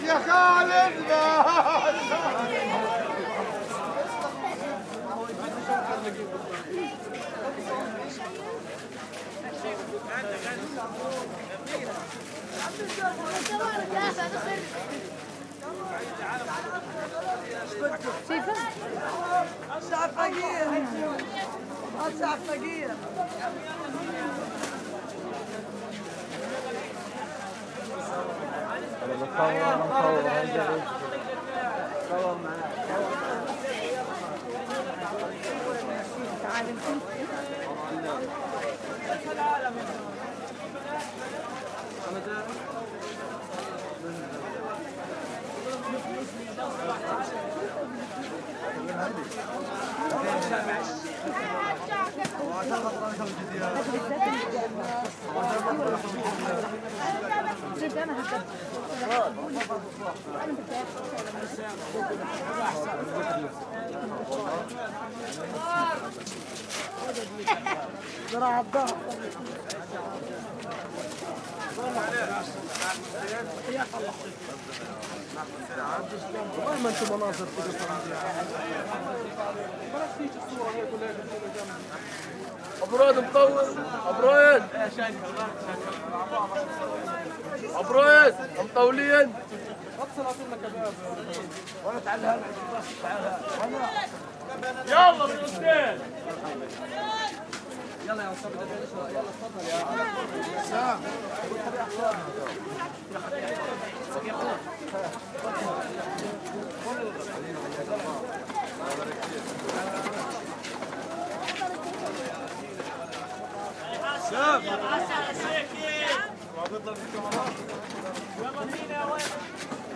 加沙 " 巴勒斯坦儿童男孩笑着胡闹 加沙 2016年
描述：巴勒斯坦儿童男孩笑着在加沙2016.wav周围徘徊
标签： 儿童 阿拉伯语 孩子们 巴勒斯坦 孩子
声道立体声